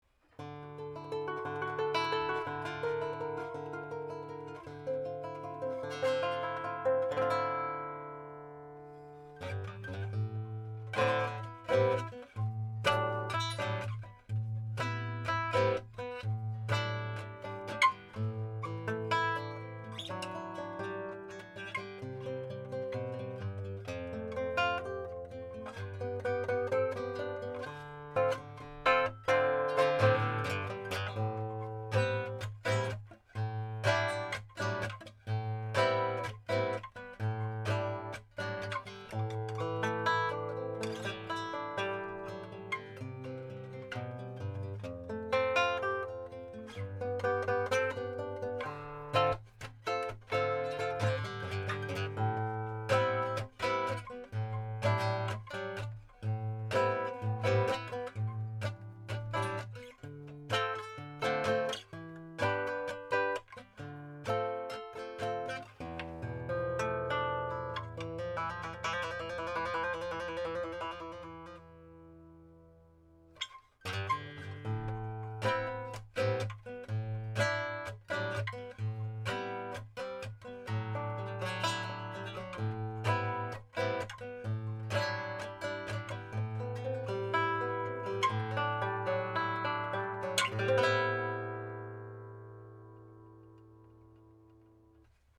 Cervantes Hauser PE Classical Guitar w/Cedar Top